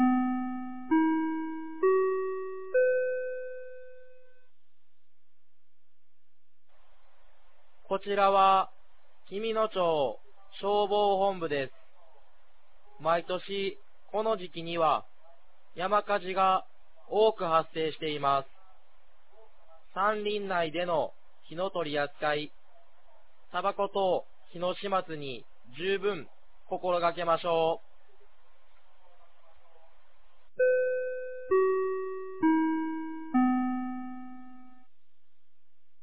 2023年05月06日 16時00分に、紀美野町より全地区へ放送がありました。